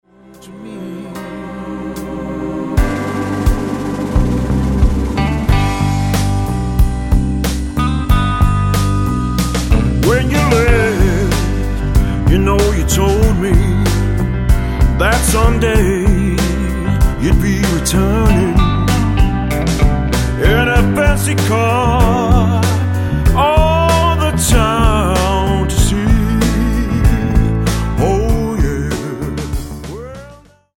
--> MP3 Demo abspielen...
Tonart:G-Ab-B Multifile (kein Sofortdownload.